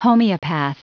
Prononciation du mot homeopath en anglais (fichier audio)
Prononciation du mot : homeopath